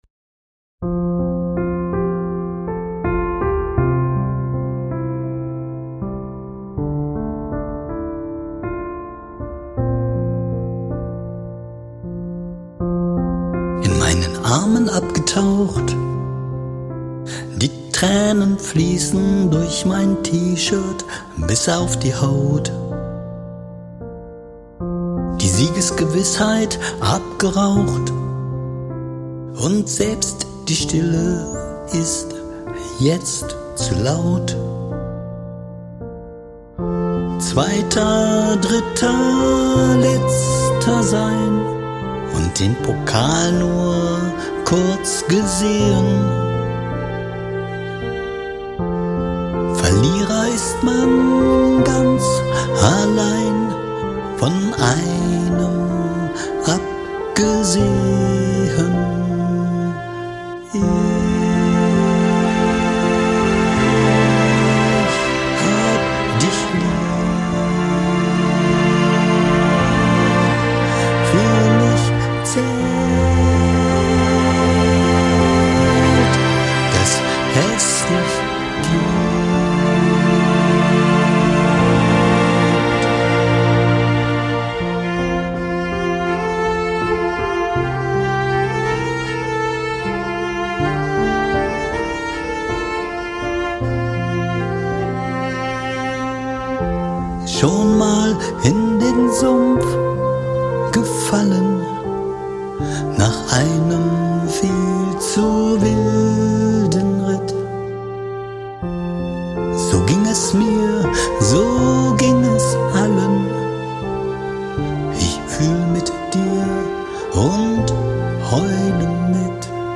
Meine Idee war, den Hall aus der Aufnahme komplett herauszurechnen.
So, jetzt hab ich fast den ganzen Sonntag Vocals geschnippelt, Kratzer Clicks und Schmatzer entfernt und doch versucht es natürlich klingen zu lassen.
Streicher habe ich auch noch mal behandelt.